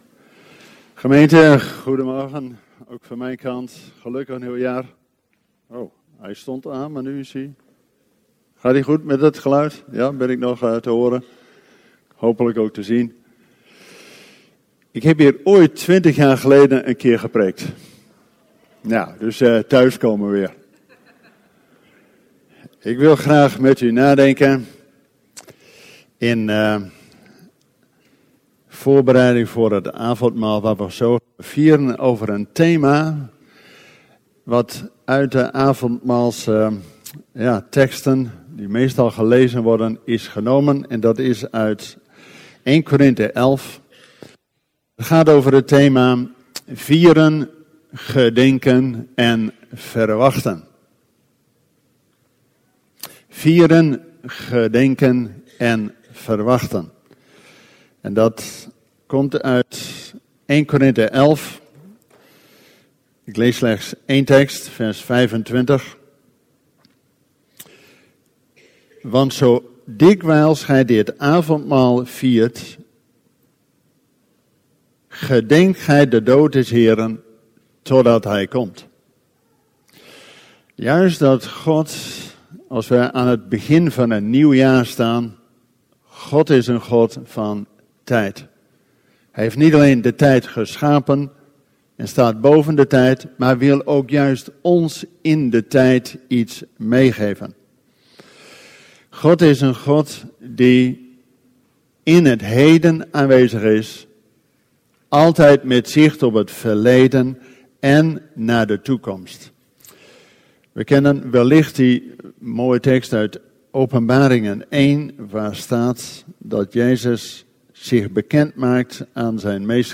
Luister hier gratis 200+ audio-opnames van preken tijdens onze evangelische diensten en blijf verbonden met Jezus!